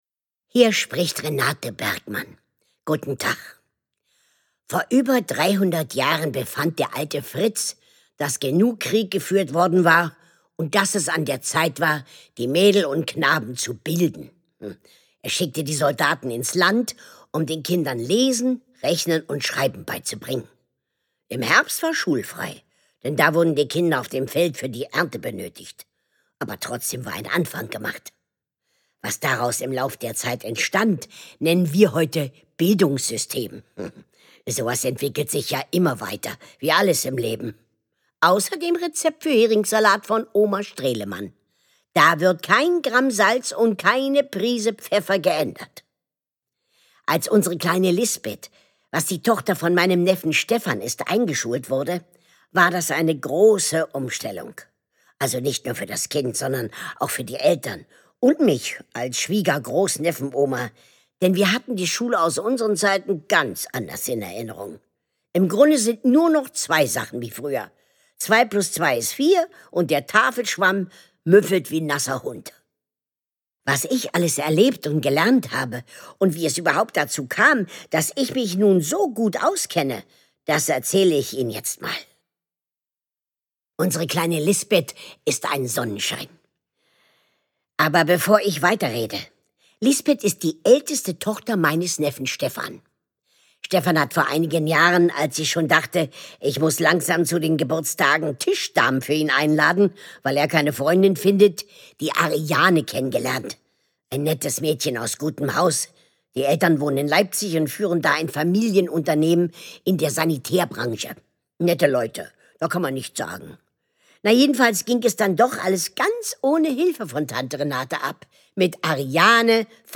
Die Online-Omi packt den Ranzen. Lesung mit Carmen-Maja Antoni (4 CDs)
Carmen-Maja Antoni (Sprecher)